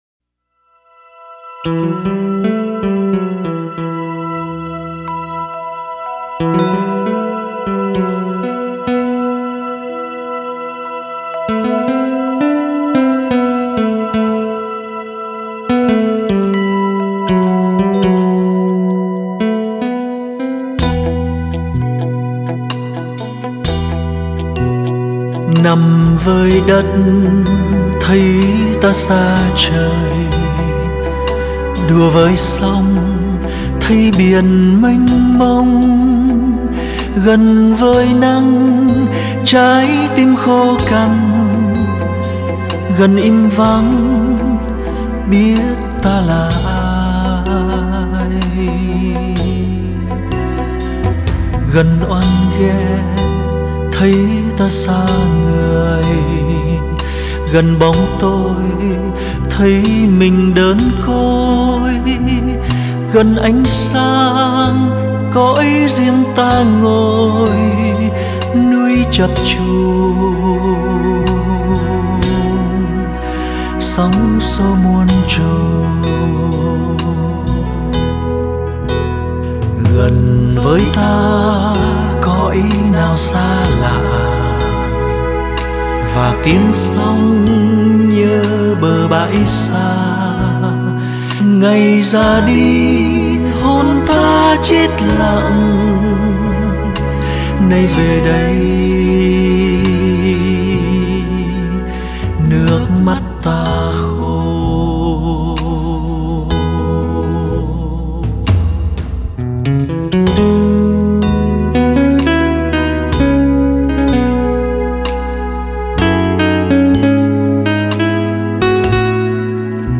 Tác giả trình bày